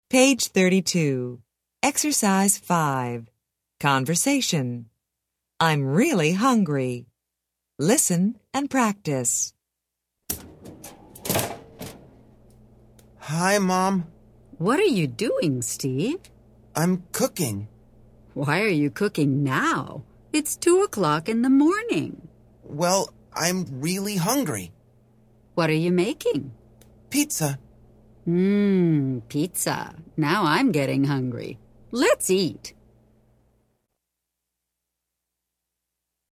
Interchange Third Edition Intro Unit 5 Ex 5 Conversation Track 14 Students Book Student Arcade Self Study Audio